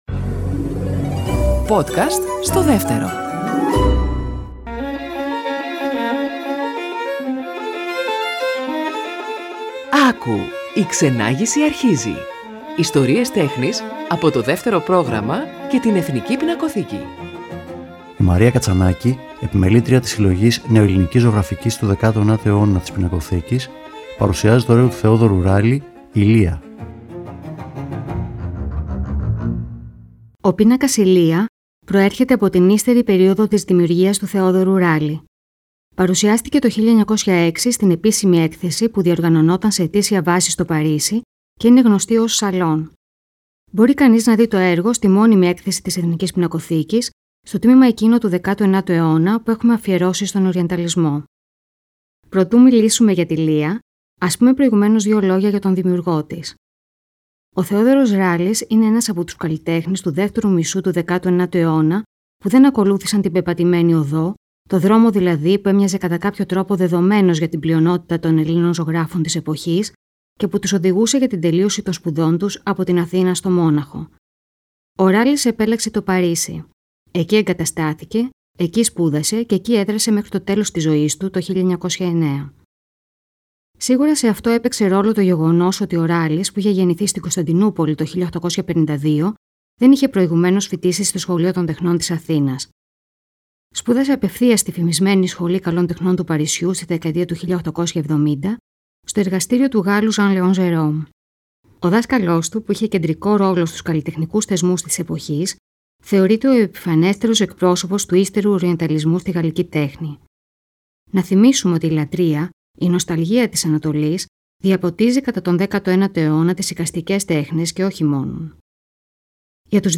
Κείμενο / Αφήγηση